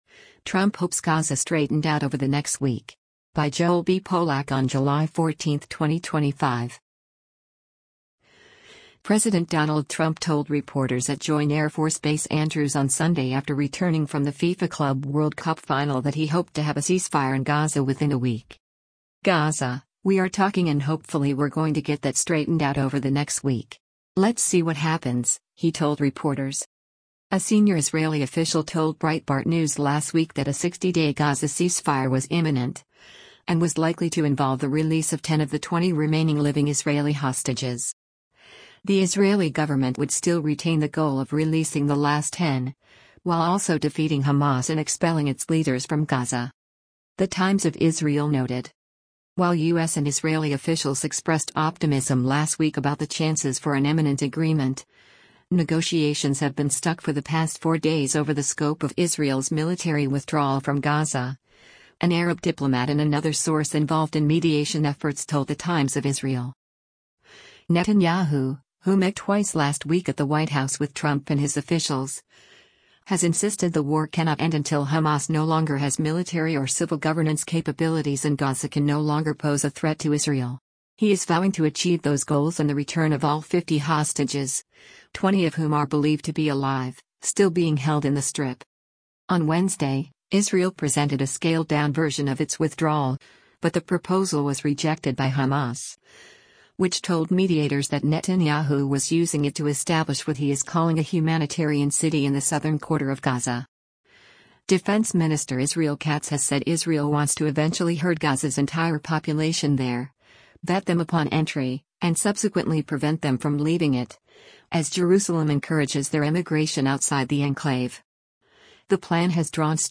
President Donald Trump speaks to the media, Sunday, July 13, 2025, on arrival to Joint Bas
President Donald Trump told reporters at Join Air Force Base Andrews on Sunday after returning from the FIFA Club World Cup final that he hoped to have a ceasefire in Gaza within a week.